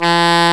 Index of /90_sSampleCDs/Roland L-CD702/VOL-2/SAX_Alto Short/SAX_Pop Alto
SAX D#2 S.wav